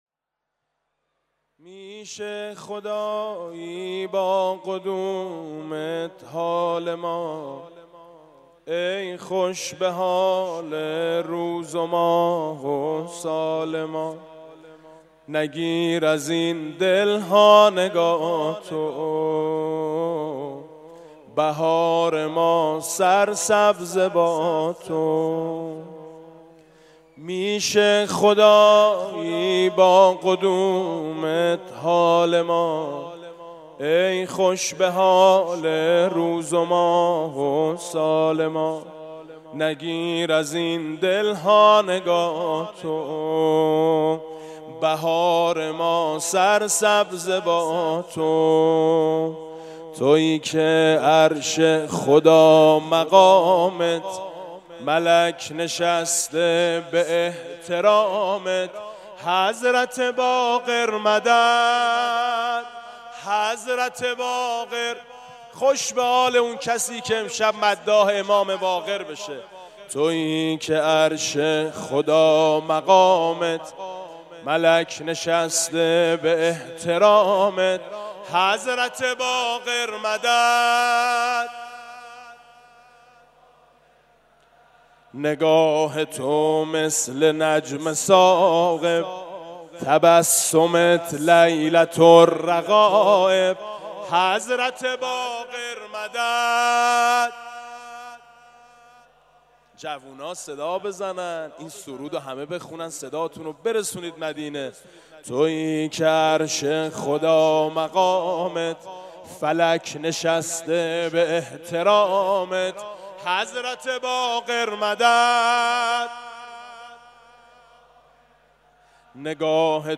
مدح: میشه خدایی با قدومت حال ما